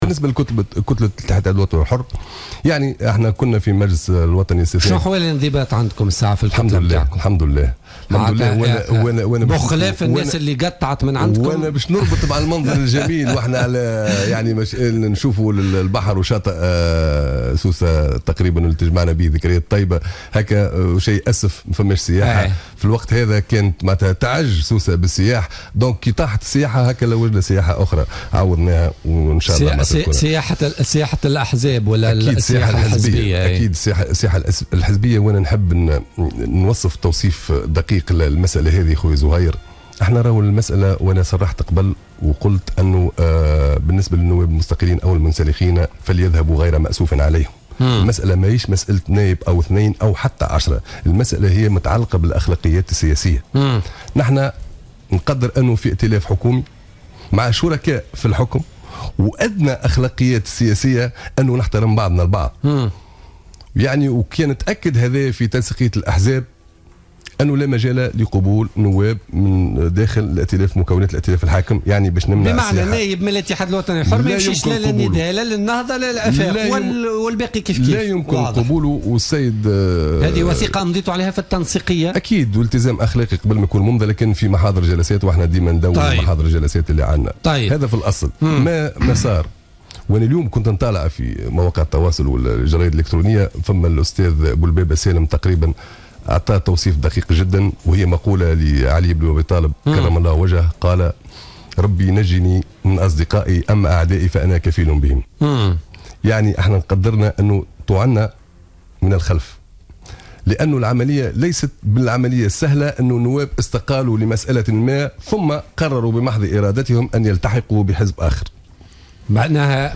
كشف طارق الفتيتي النائب بمجلس نواب الشعب عن الاتحاد الوطني الحر والمستقيل من رئاسة كتلة حزبه الاثنين الفارط خلال حضوره ضيفا في برنامج بوليتكا لليوم الأربعاء 18 ماي 2016 أن عددا من النواب المستقيلين من الحزب لم يستقيلوا بمحض إرادتهم وإنما تم استدراجهم واستقطابهم وفق تعبيره.